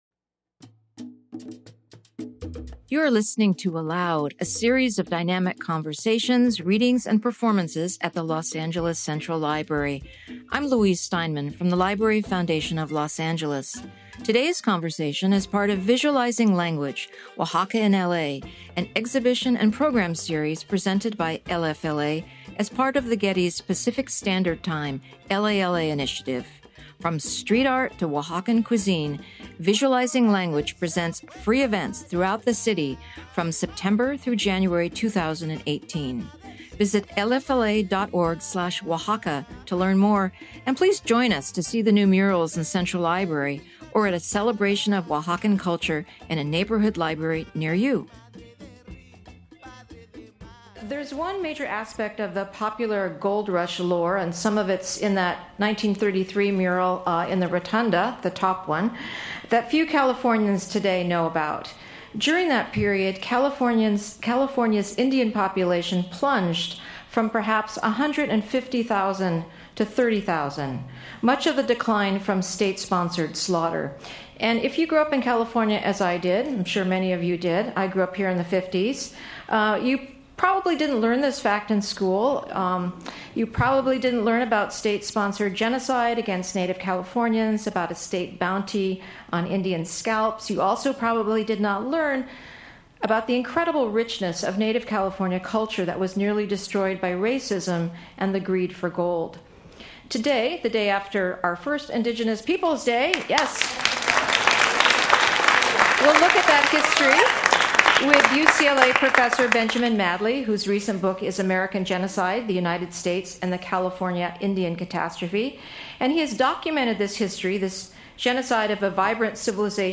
With Invocation and Reading